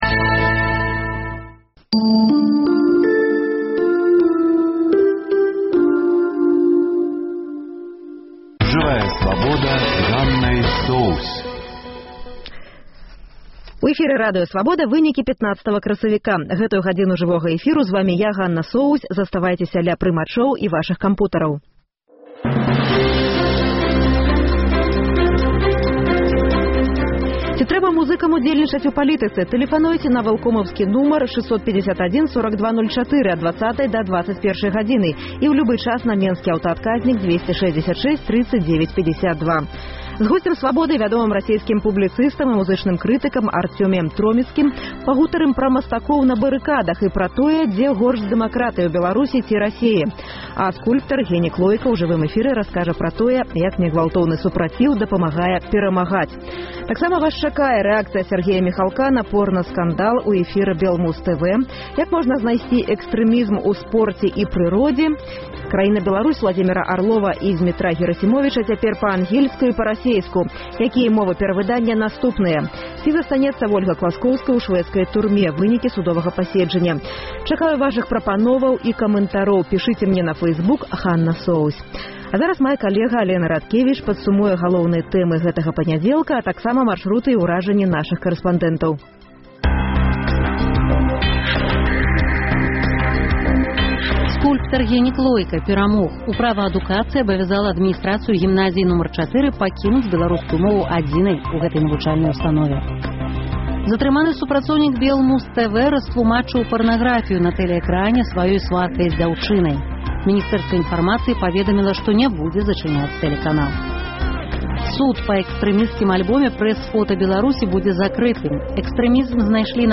З госьцем «Свабоды» вядомым расейскім публіцыстам і музычным крытыкам Арцёміем Троіцкім пагаворым пра масткоў на барыкадах, беларускіх музыкаў у Расеі і пра тое, дзе горш з дэмакратыяй — у Беларусі ці Расеі.